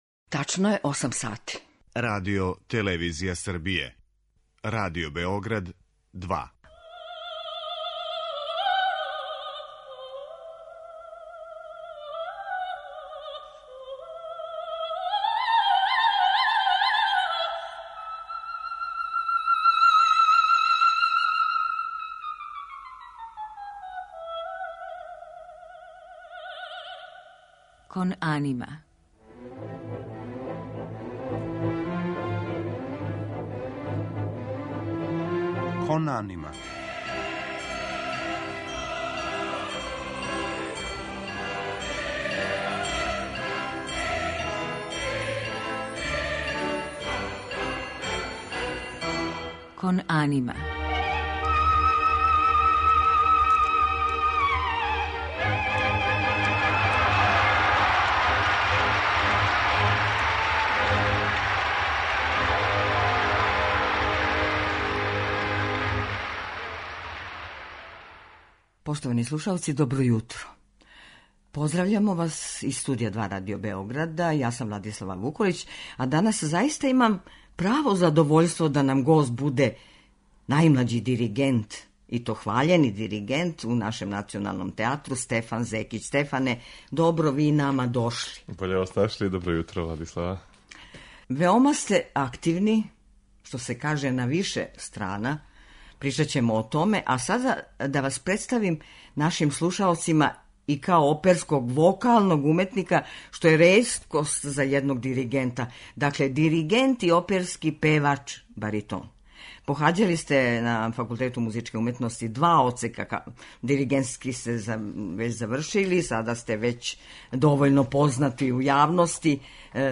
У његовој интерпретацији слушаћемо и фрагменте из опера Ђузепа Вердија, Волфганга Амадеуса Моцарта и Клаудија Монтевердија.